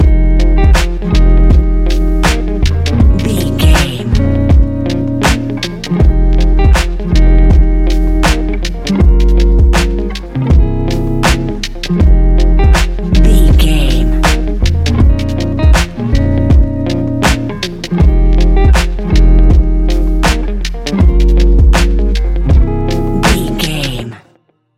Ionian/Major
F♯
laid back
Lounge
sparse
new age
chilled electronica
ambient
atmospheric
morphing